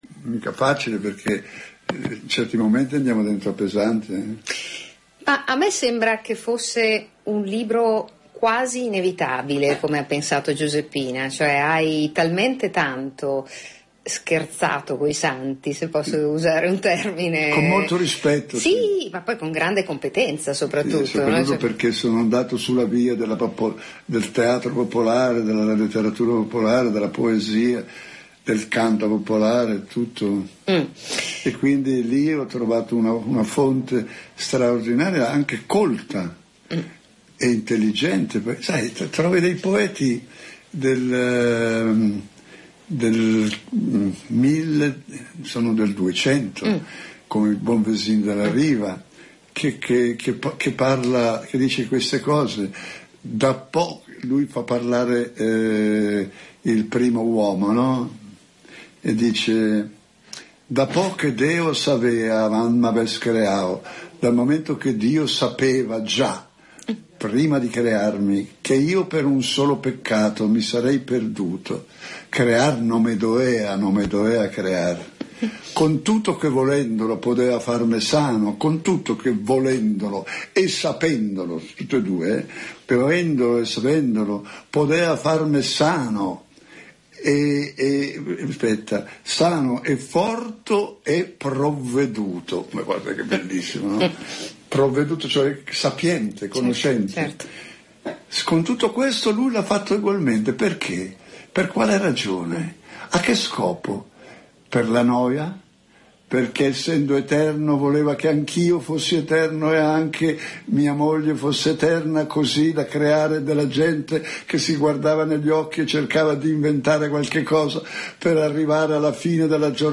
Dario e Dio: una conversazione con Dario Fo a partire dalla religione | Radio Popolare